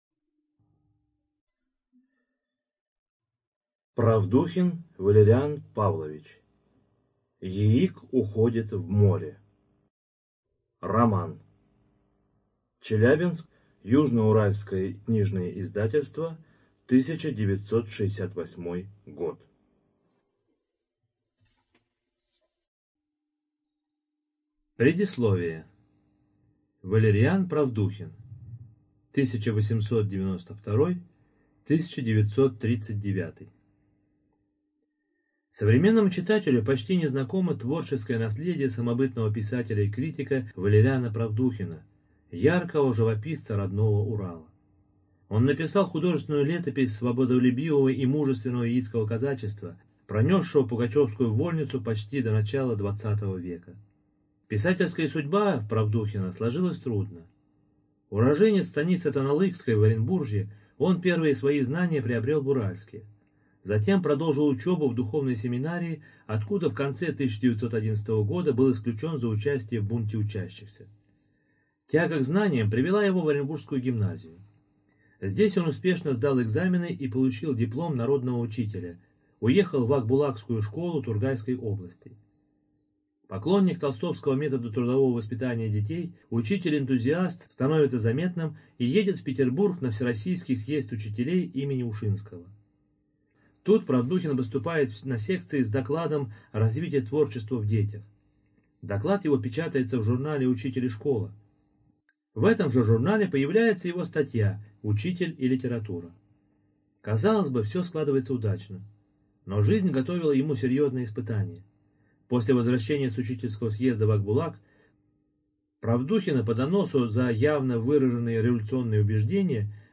Студия звукозаписиЗападно-Казахстанская библиотека для незрячих и слабовидящих граждан